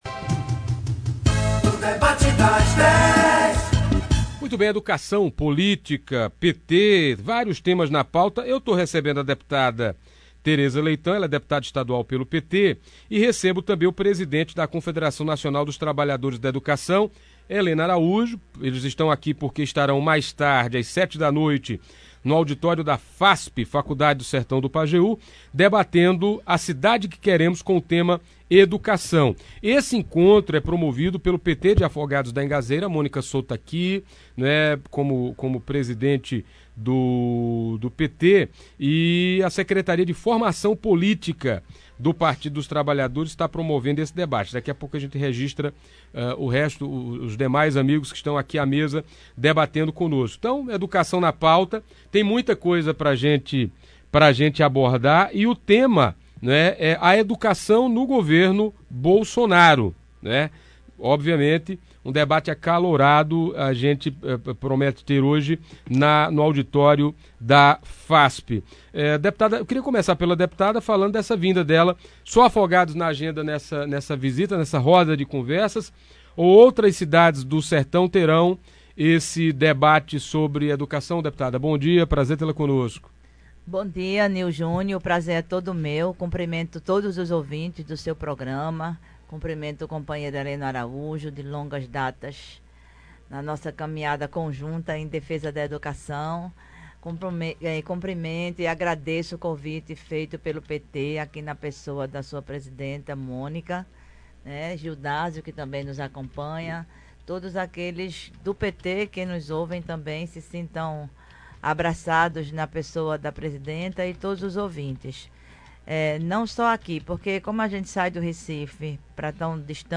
Ouça abaixo a íntegra do debate: Ao falar sobre as eleições de 2020, Tereza foi provocada a falar se Marília Arraes corria o risco de ser novamente rifada como aconteceu nas eleições de 2018.